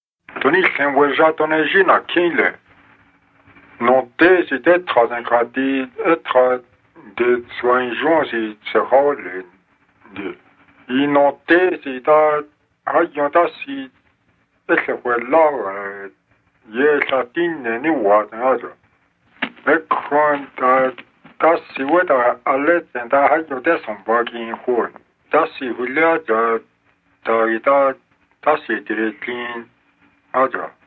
27 November 2016 at 5:27 pm Sounds like someone starting off speaking French with a French accent, and then changing over to Navaho, so I’m really curious to find out what it is.
28 November 2016 at 1:33 pm This language has nothing to do with French, but does have nasal vowels, and is a Na-Dené language.